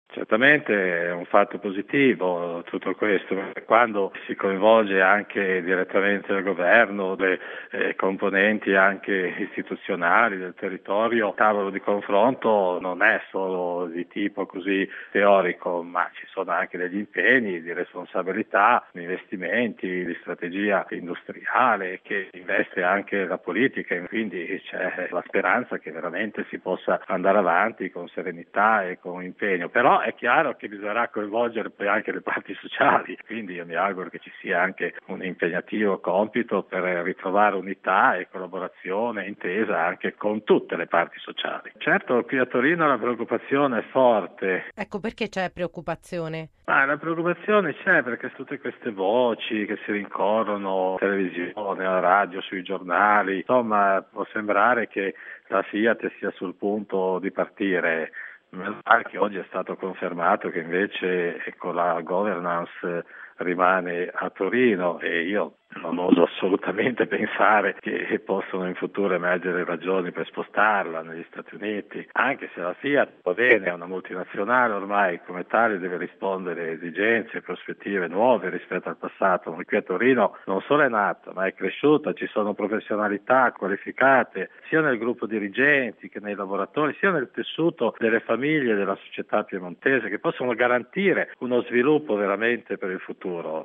Giudizio positivo anche dall’arcivescovo di Torino, mons. Cesare Nosiglia.